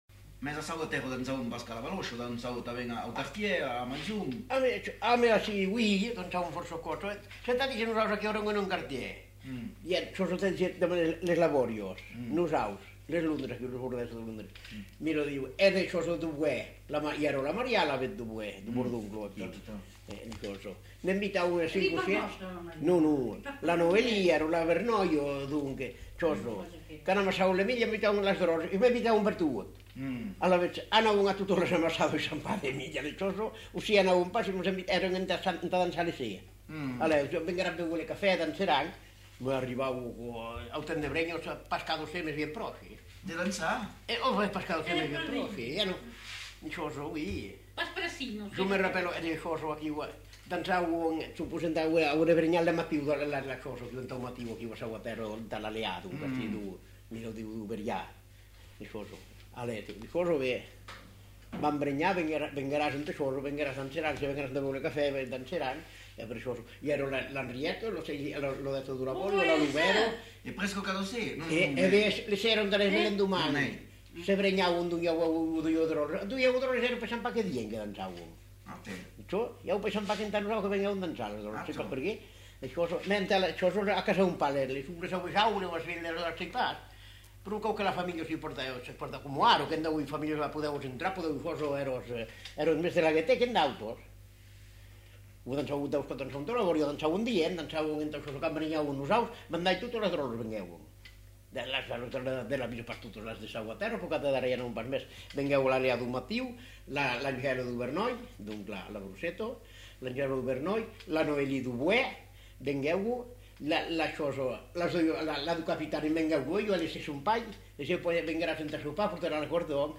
Aire culturelle : Savès
Lieu : Espaon
Genre : témoignage thématique